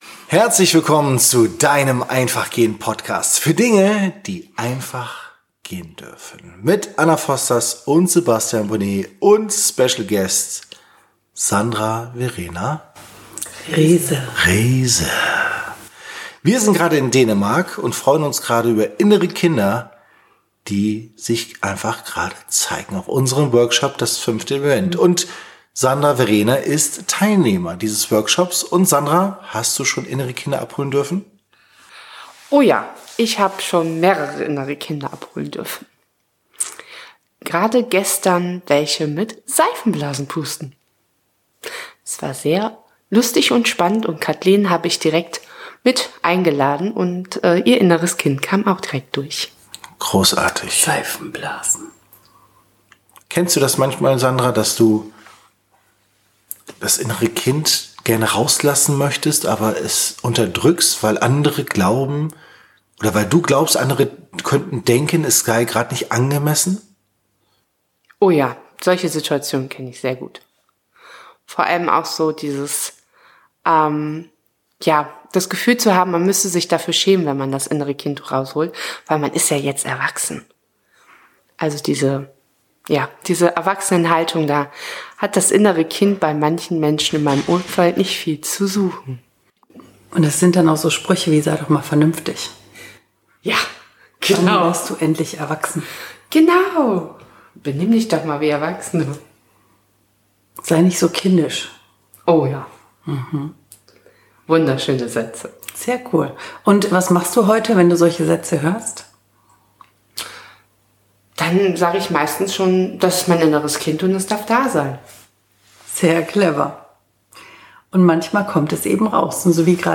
Dieser Podcast wurde in Dänemark im Rahmen unseres Workshops "Das